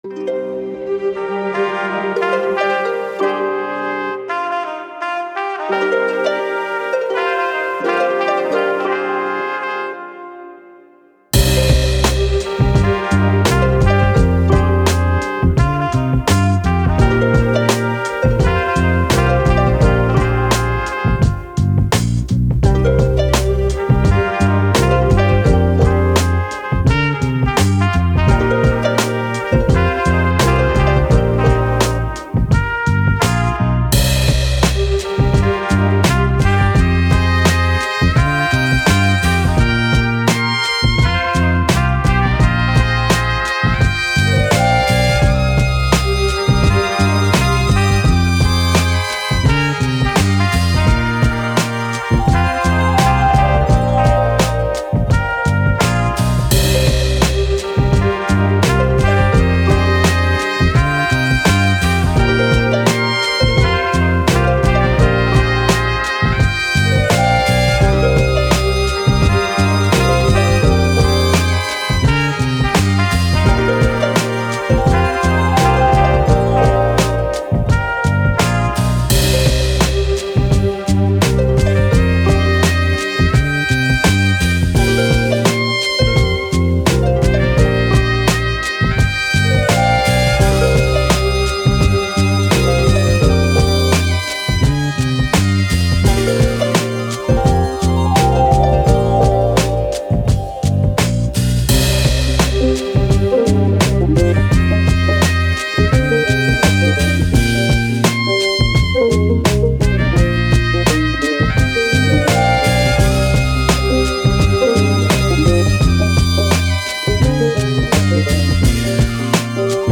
Hip Hop, Vintage, Upbeat, Vibe